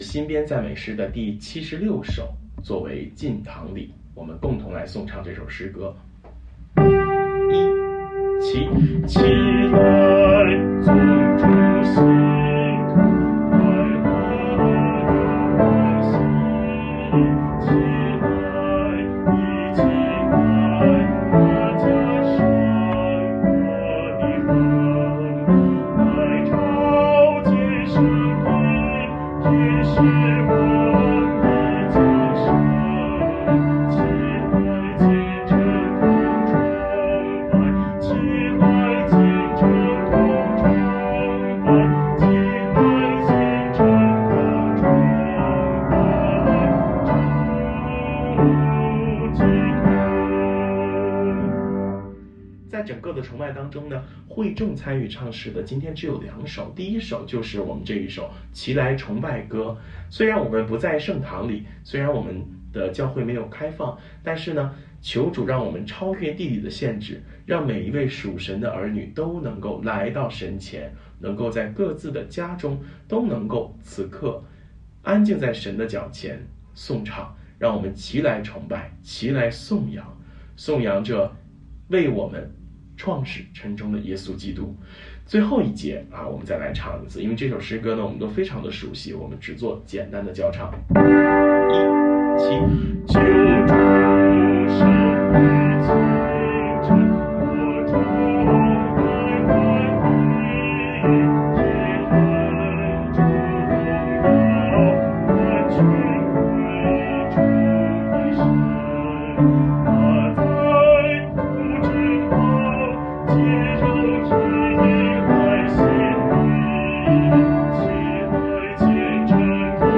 进堂殿乐         新编赞美诗76《齐来崇拜歌》        进堂